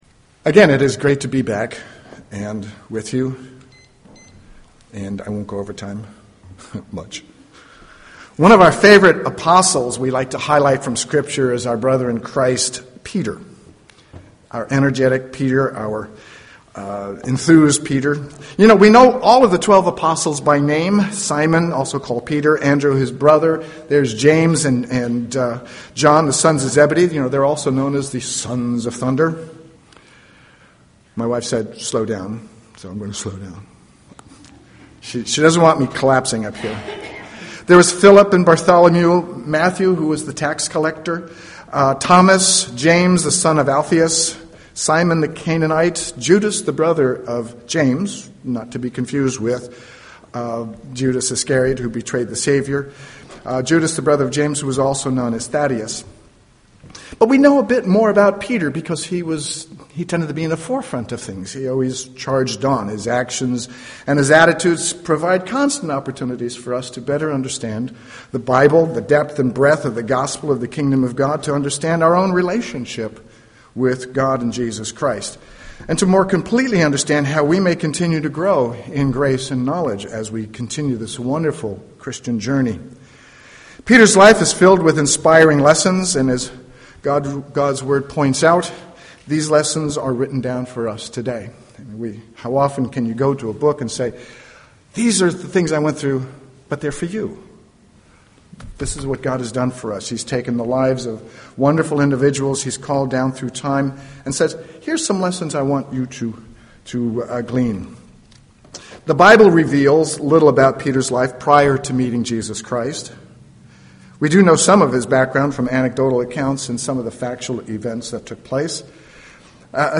Given in San Jose, CA
UCG Sermon Studying the bible?